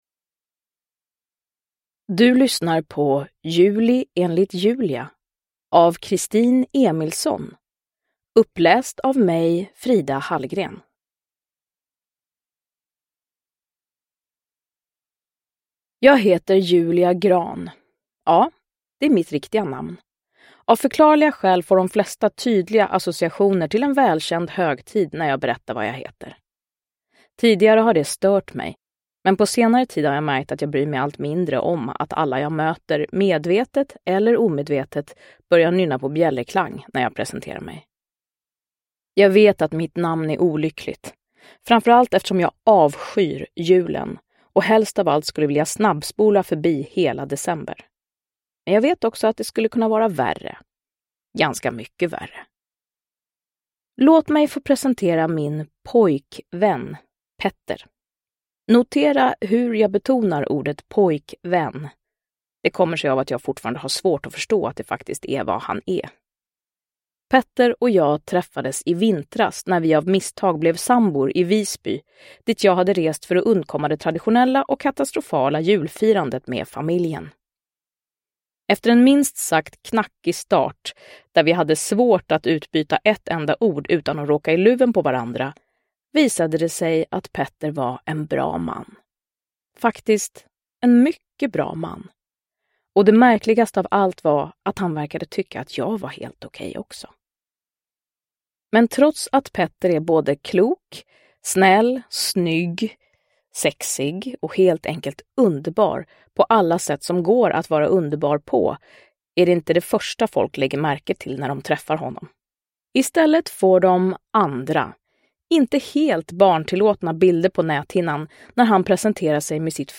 Juli enligt Julia – Ljudbok – Laddas ner
Uppläsare: Frida Hallgren